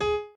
b_pianochord_v100l8o5gp.ogg